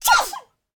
mob / panda / sneeze3.ogg
sneeze3.ogg